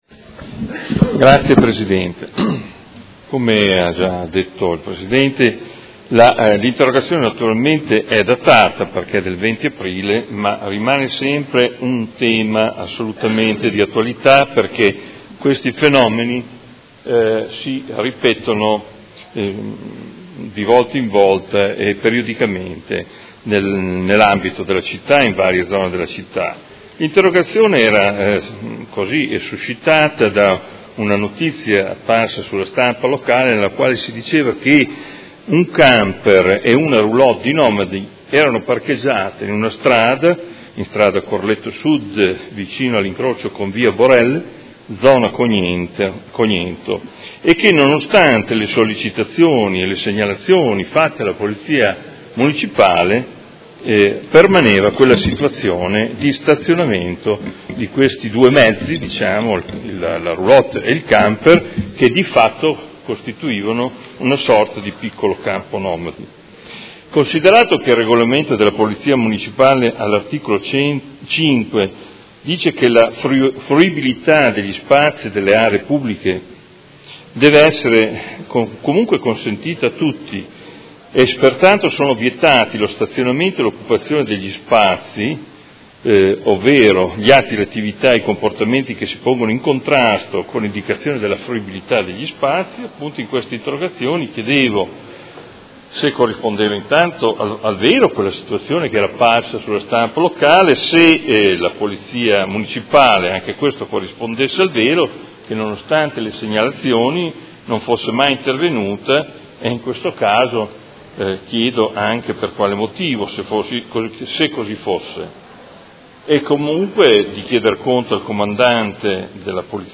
Seduta del 28/06/2018. Interrogazione del Consigliere Morandi (FI) avente per oggetto: Campo nomadi abusivo a Cognento